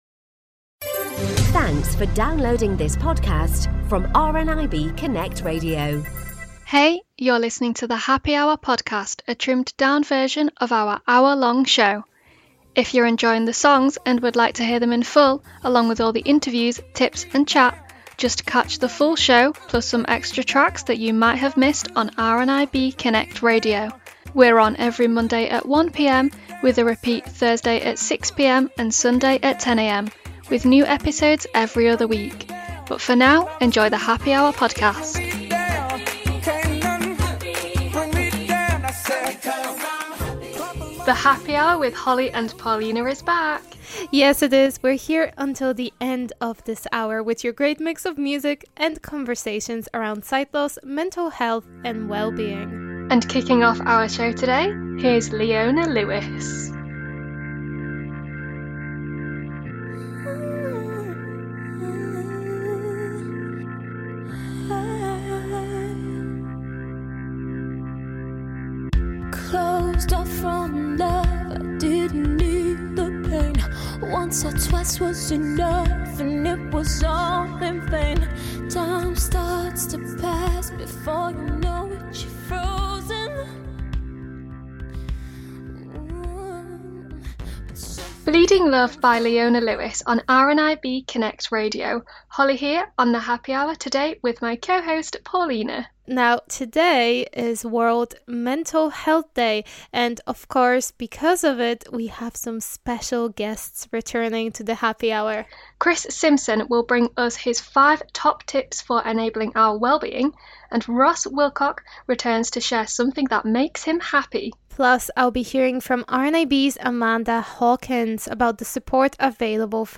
We even have a few returning guests and a special appearance from Paralympian Naomi Riches MBE!
And yes, gentle sounds of the waves on sandy shores are included.